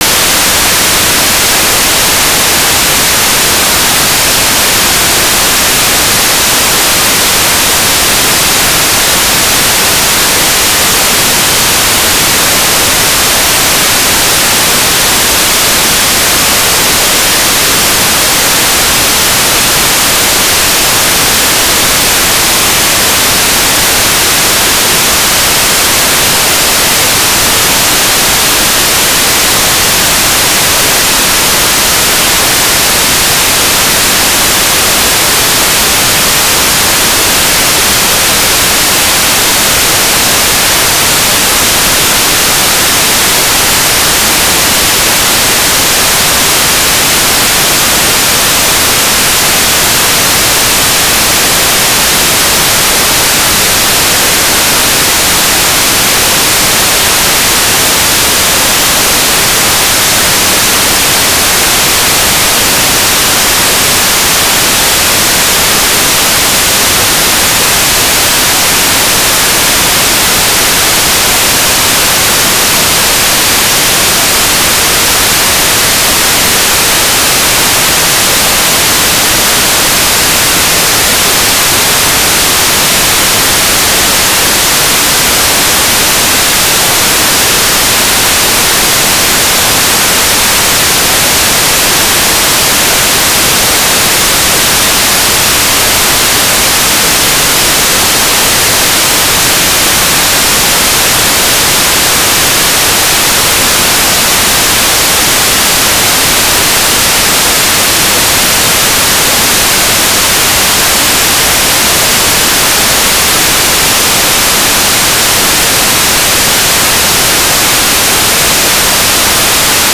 "transmitter_description": "1k2 AFSK Telemetry",
"transmitter_mode": "AFSK",